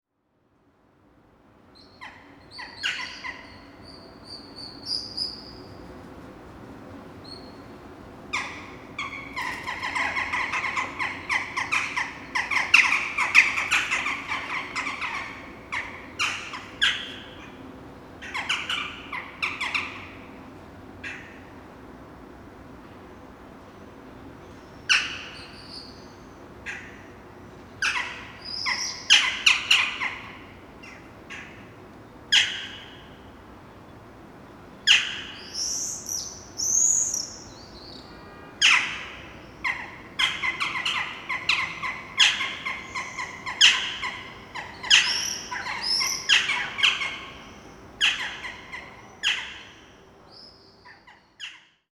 Up to 40 individuals of Jackdaws were screeming every morning flying around
in the inner courtyard.
090719, Western Jackdaw Corvus monedula, calls, Kronach, Germany
wjackdaw.mp3